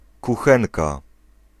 Ääntäminen
France (Paris): IPA: [ɛ̃ fuʁ]